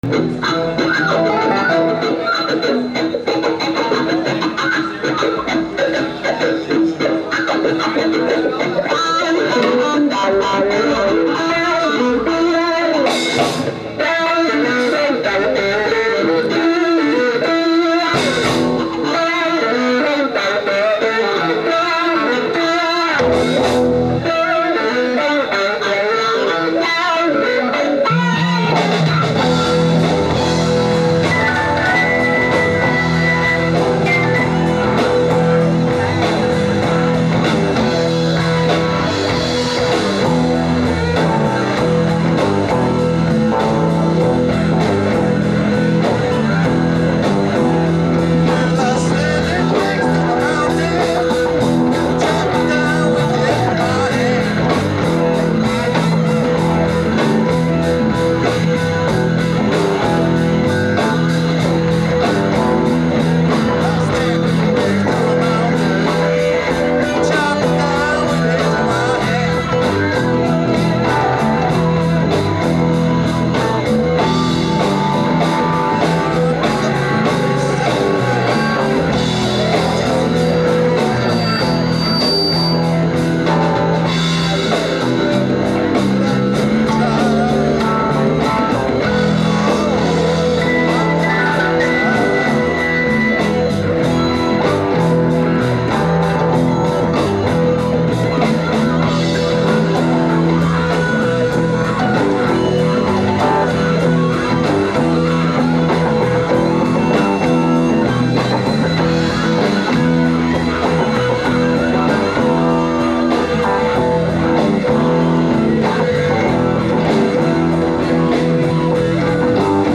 Please understand that not only were these outdoor audience recordings made under extremely difficult conditions, but also that much of the band had not even met before the party, let alone practiced together, and the jam lasted well under an hour.
It's interesting how the mixes sound different due to the recorder locations...